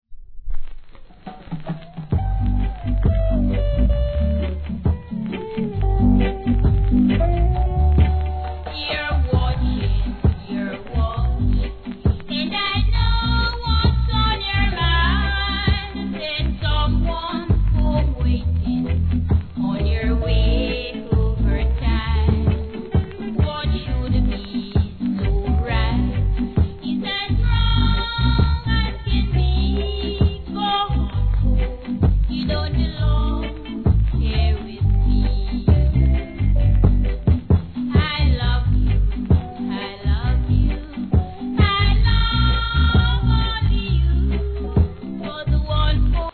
盤面にスレがありますが見た目ほどノイズありません（試聴確認ください）
REGGAE